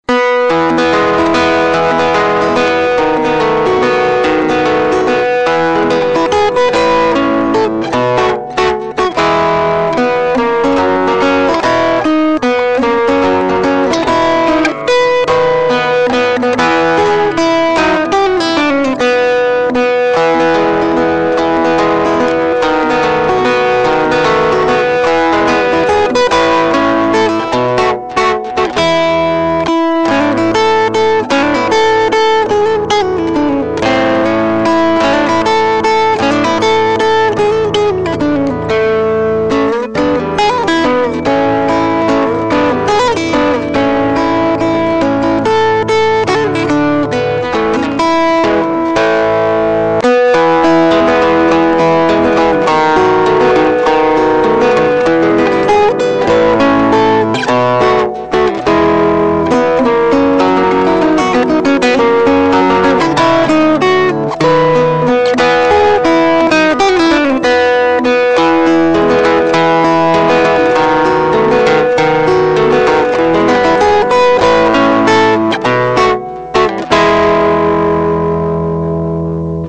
Gonzo improvisation on Fernando Sor's Opus 35 Number 8 (mp3, 1245 Kby)